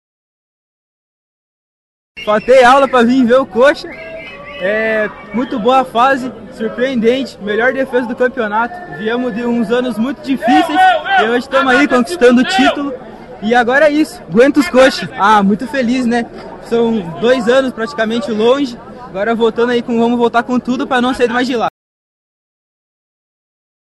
A chegada da equipe transformou o saguão em um corredor verde e branco, com cantos, bandeiras e muita comemoração pela conquista da Série B do Campeonato Brasileiro.